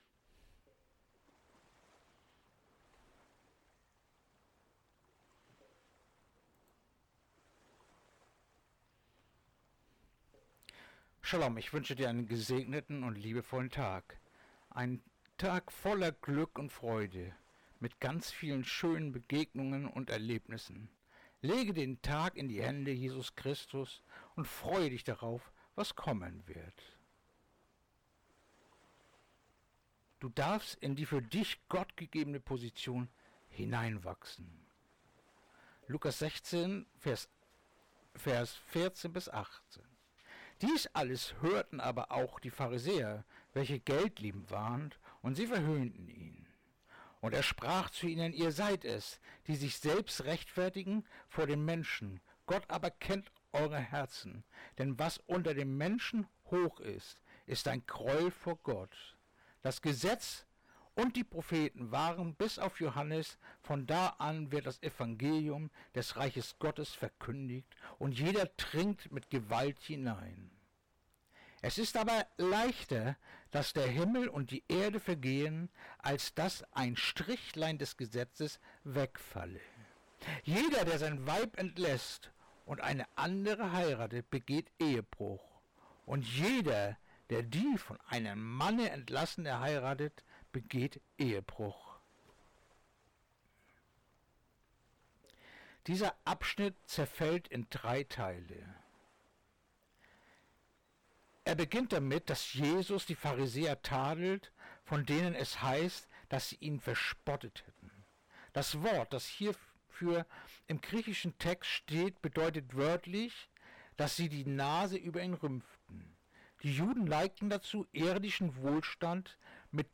Andacht-vom-9-September-Lukas-16-14-18